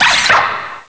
pokeemerald / sound / direct_sound_samples / cries / axew.aif